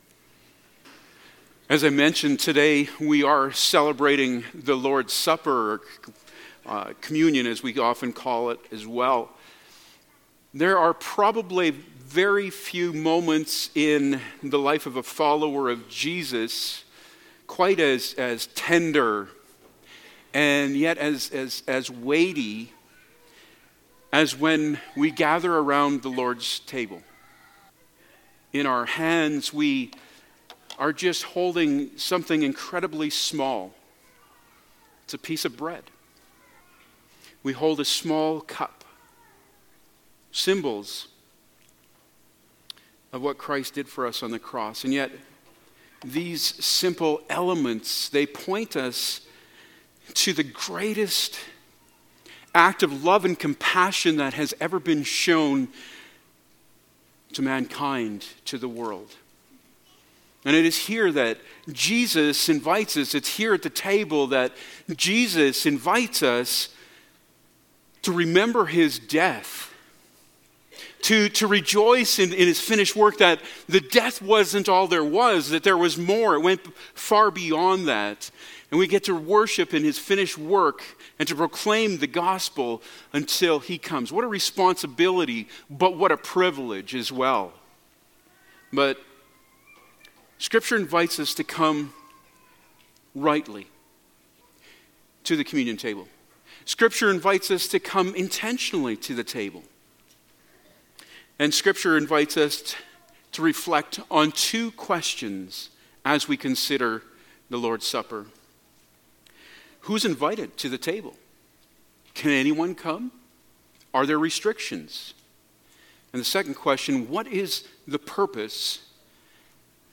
Passage: 1 Corinthians 11:17-34 Service Type: Sunday Morning Topics: Communion « Jesus